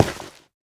Minecraft Version Minecraft Version snapshot Latest Release | Latest Snapshot snapshot / assets / minecraft / sounds / block / nylium / step4.ogg Compare With Compare With Latest Release | Latest Snapshot
step4.ogg